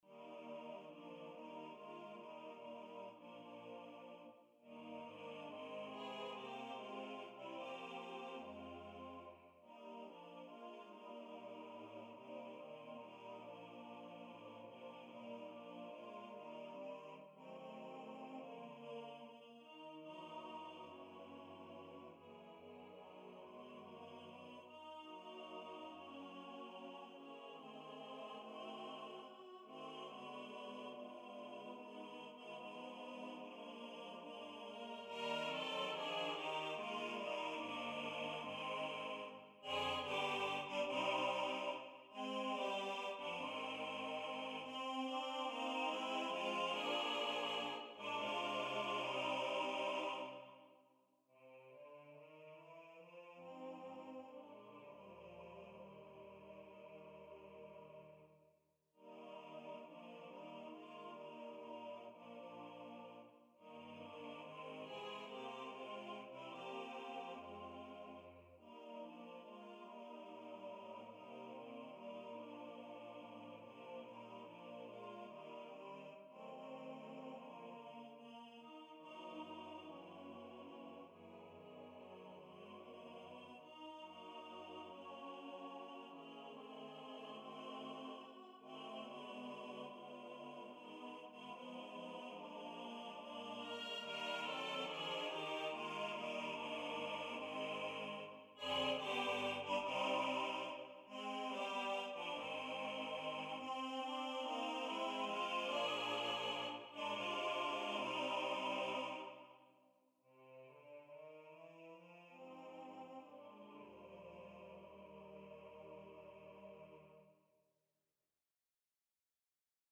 Voices: T1, T2, B1, B2 Instrumentation: a cappella
NotePerformer 4 mp3 Download/Play Audio